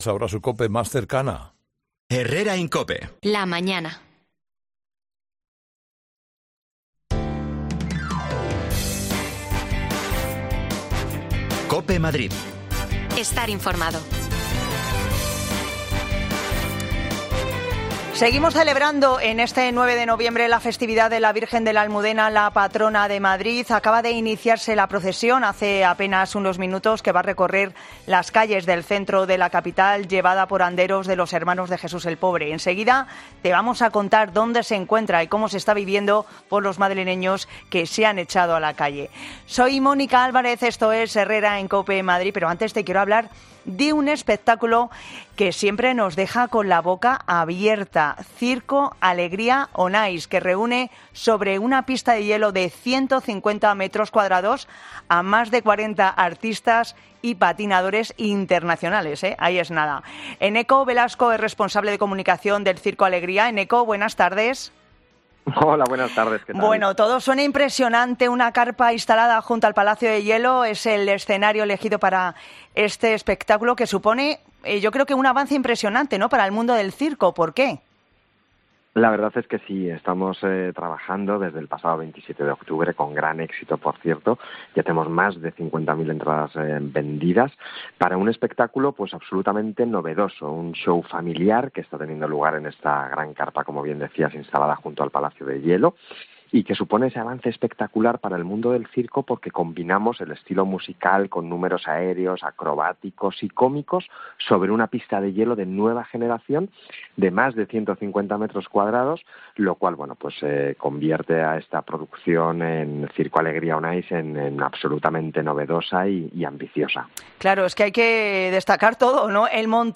AUDIO: Salimos a la calle en este dia en el que celebramos el Dia de la Almudena